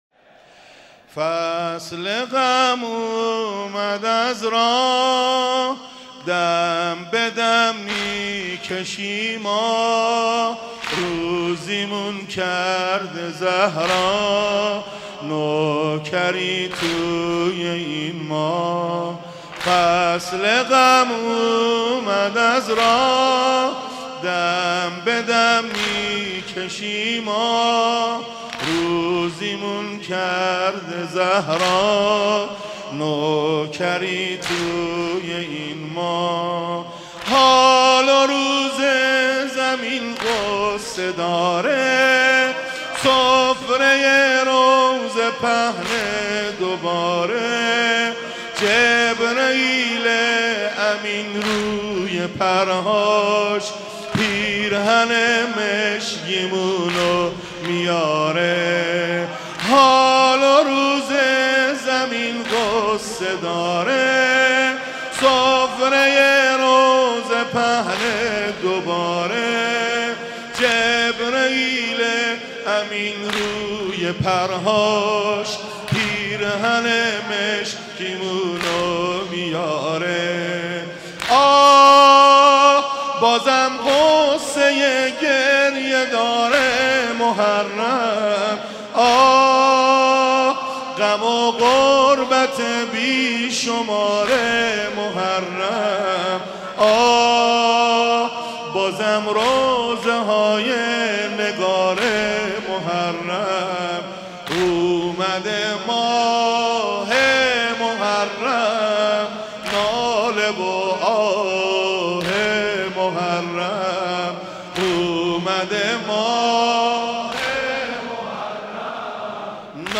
مراسم شب اول محرم 94